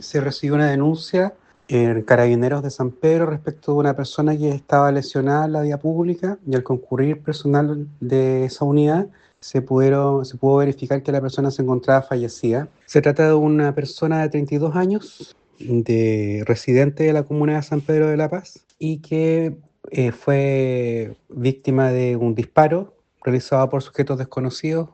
La víctima fue abordada por desconocidos que le dispararon, dijo el fiscal de Análisis Criminal, Felipe Calabrano.